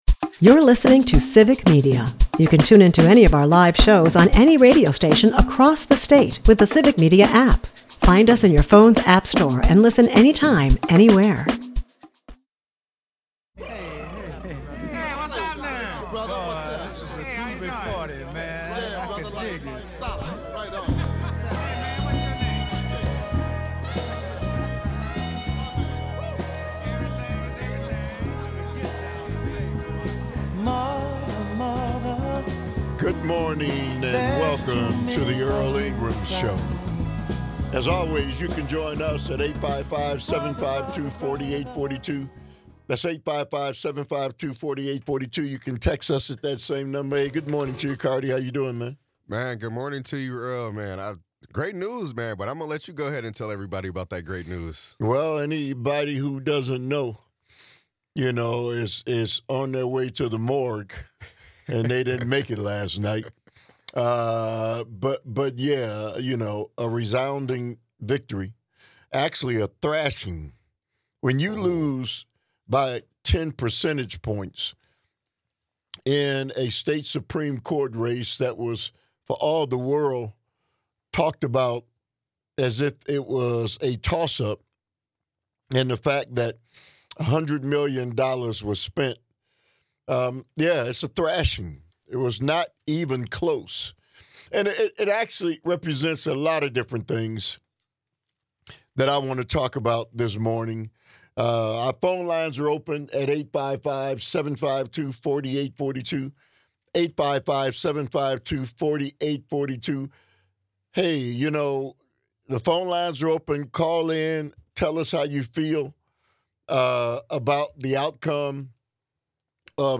He invites callers to share their perspectives on the significance of this election. The discussion includes the expansion of train travel linking Minnesota, Illinois, and Wisconsin.